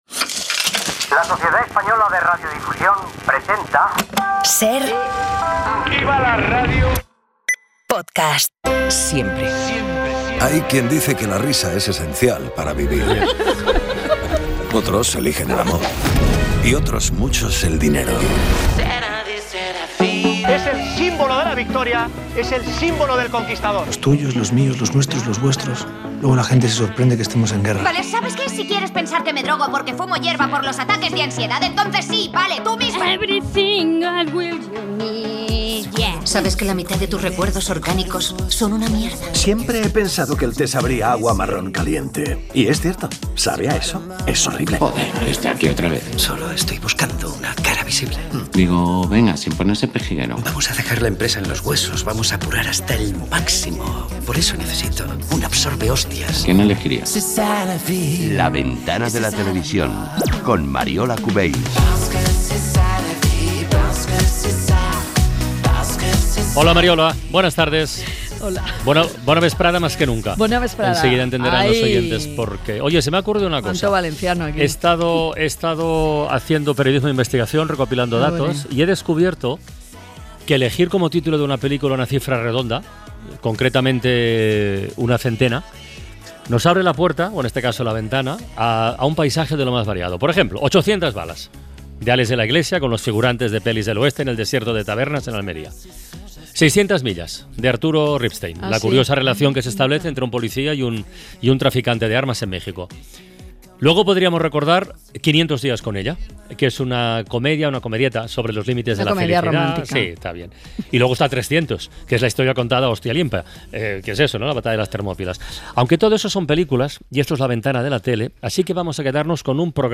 El cómico Pere Aznar explica en 'La Ventana de la Tele' las características de '400' el programa de humor de la televisión autonómica valenciana.